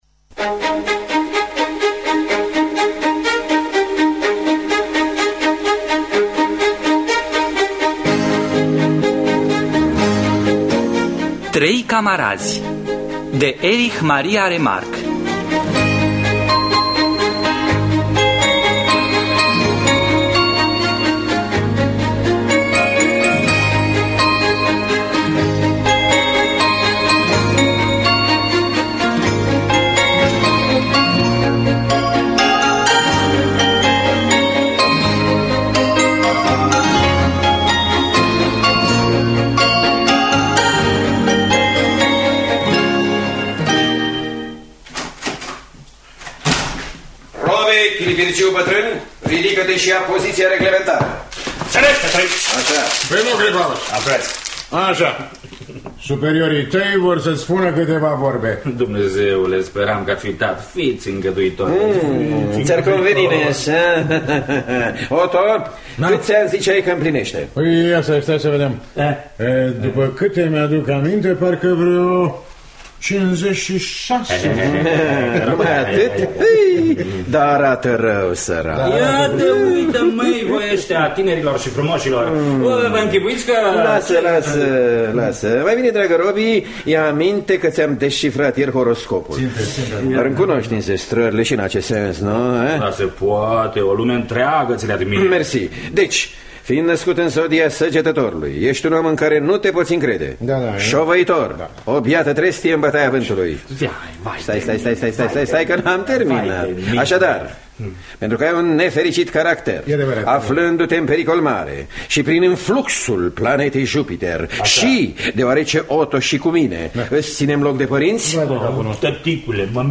Dramatizarea radiofonică